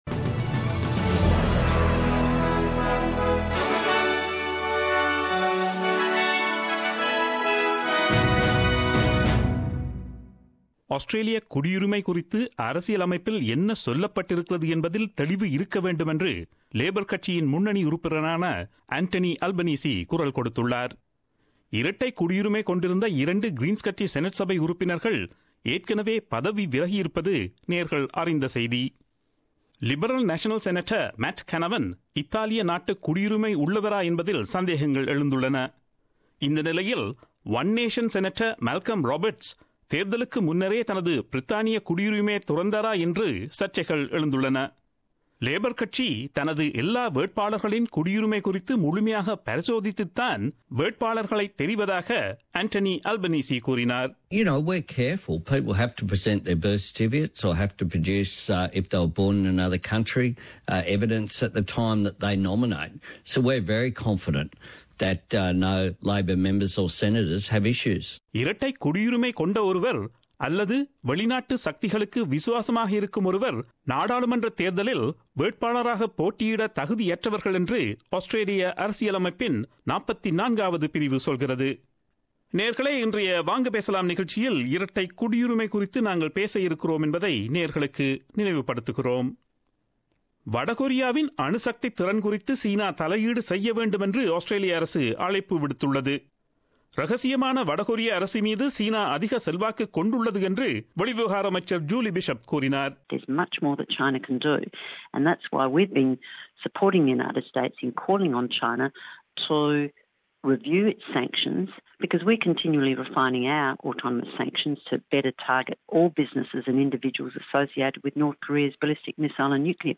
Australian news bulletin aired on Friday 28 July 2017 at 8pm.